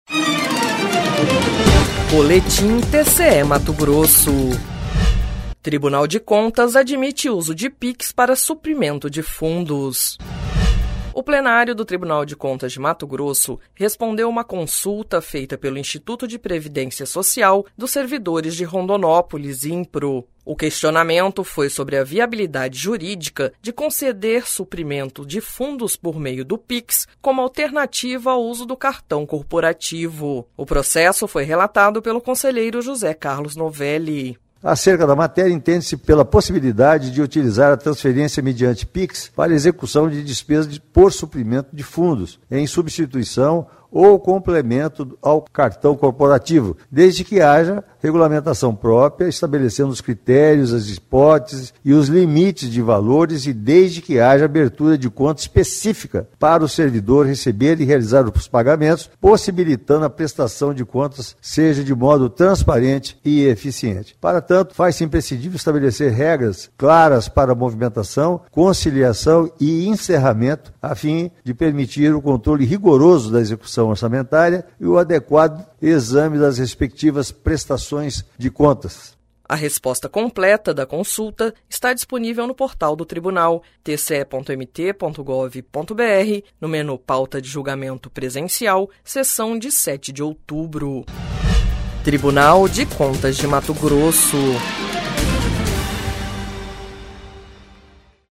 Sonora: José Carlos Novelli - – conselheiro do TCE-MT